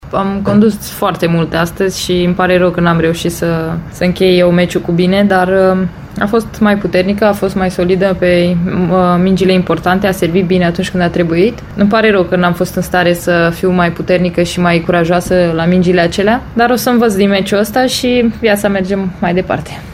‘Sufăr acum, trebuie să recunosc, dar viaţa merge înainte’, a declarat Halep la conferinţa de presă ce a urmat, potrivit site-ului WTA citat de Agerpres.